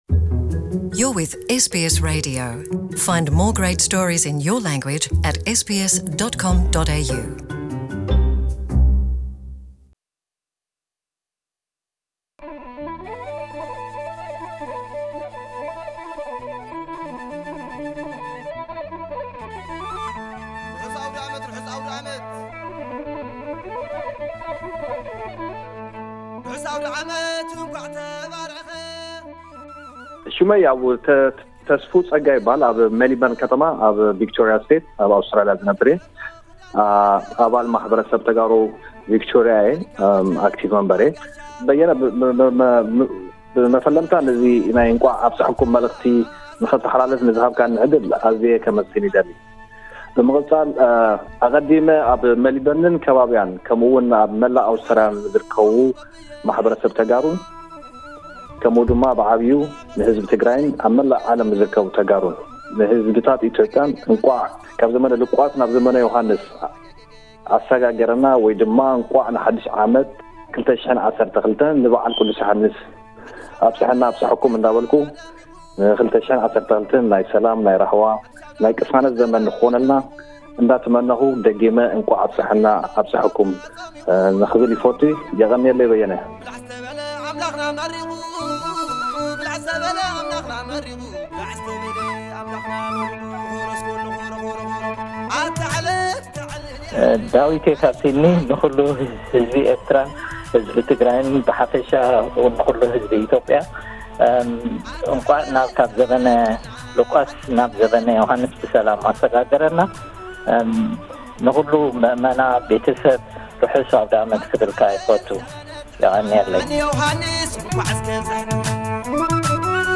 ናብዚ ንኣትዎ ዘለና ሓዲሽ ዓመት 2012 ከምኡውን በዓል ቅዱስ ዮሃንስ ምኽንያት ብምግባር ኣብ ኣውስትራሊያ ዝርከቡ ገለ ኣባላት ማሕበረሰብ ዝሃብዎ ናይ ሰናይ ትምኒት መልእኽቲ።